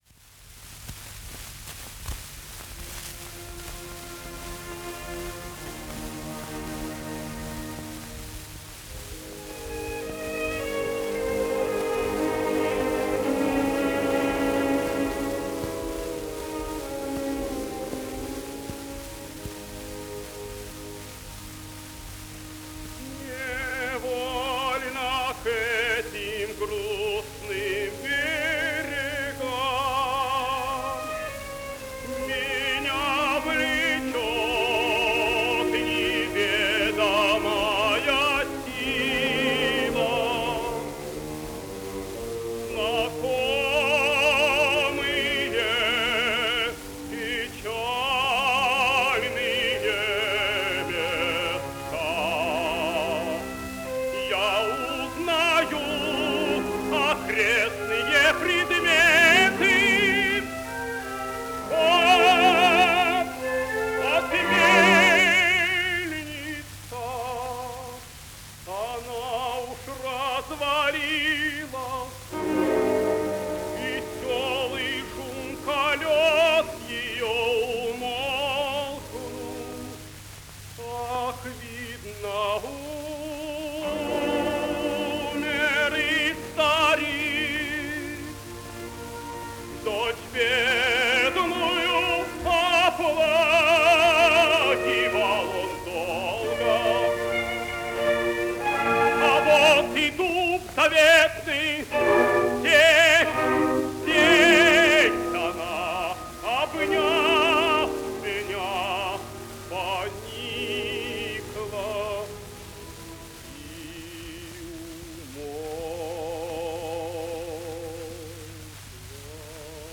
Каватина Князя. Оркестр ГАБТ СССР. Дирижёр В. Л. Кубацкий. Исполняет С. Н. Стрельцов.
Обладал сильным лирико-драматическим тенором широкого диапазона, мягкого тембра с характерной окраской, драматическим и комедийным талантом.